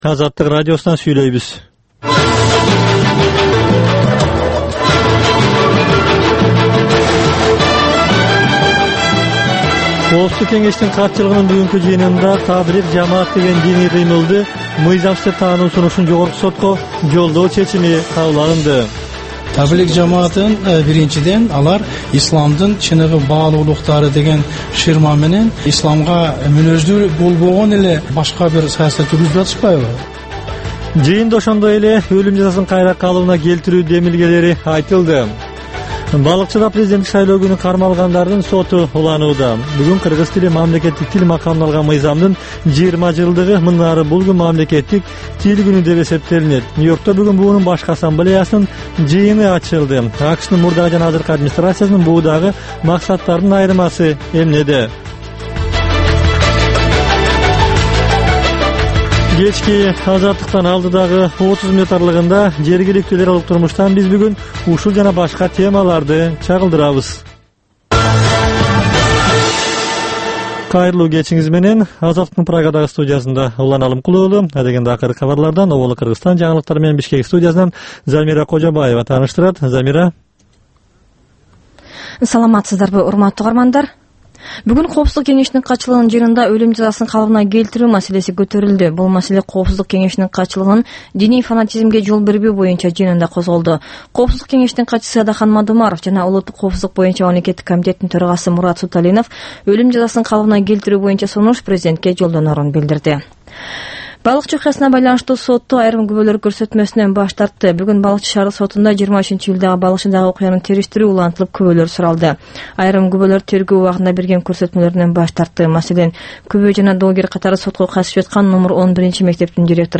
Бул кечки үналгы берүү жергиликтүү жана эл аралык кабарлардан, репортаж, маек, баян жана башка берүүлөрдөн турат. "Азаттык үналгысынын" бул кечки берүүсү ар күнү Бишкек убактысы боюнча саат 21:00ден 21:30га чейин обого түз чыгат.